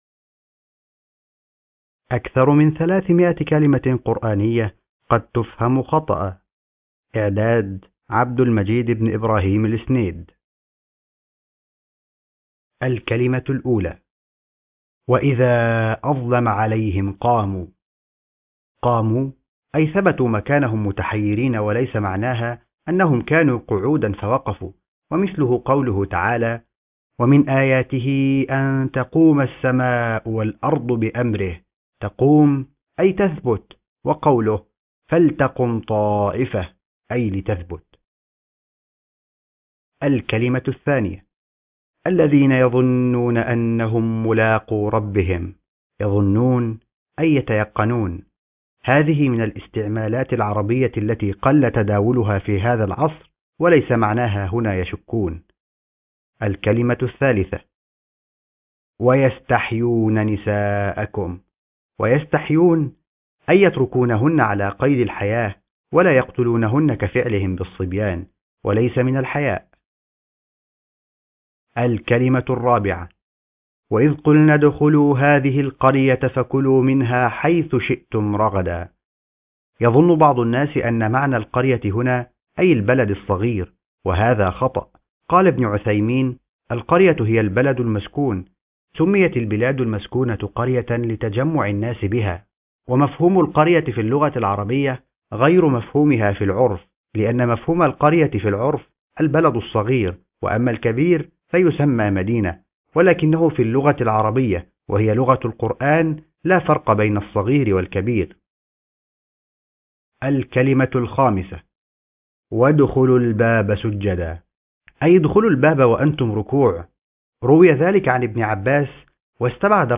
أكثر من 300 كلمة قرآنية قد تفهم خطأ ( كتاب صوتي مقروء )